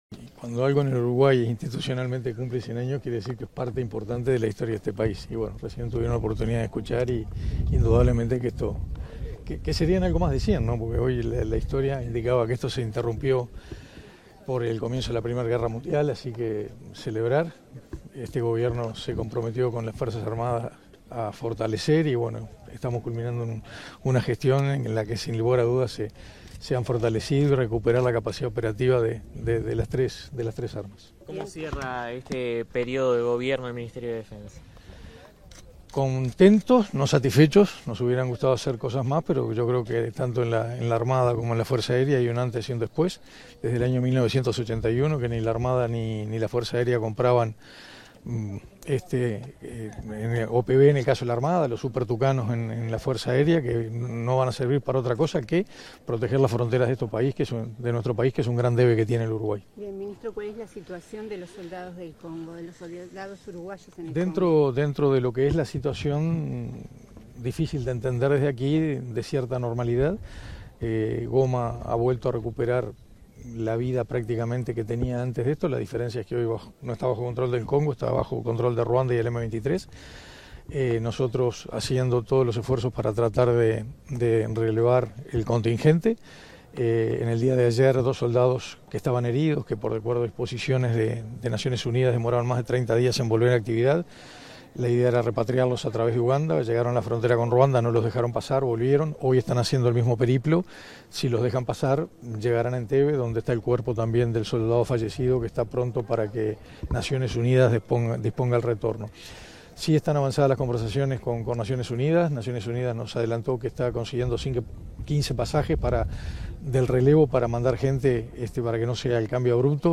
Declaraciones del ministro de Defensa Nacional, Armando Castaingdebat
Declaraciones del ministro de Defensa Nacional, Armando Castaingdebat 07/02/2025 Compartir Facebook X Copiar enlace WhatsApp LinkedIn Tras participar en el acto conmemorativo de los 100 años de la creación de la Aviación Naval, este 7 de febrero, el ministro de Defensa Nacional, Armando Castaingdebat, realizó declaraciones a la prensa.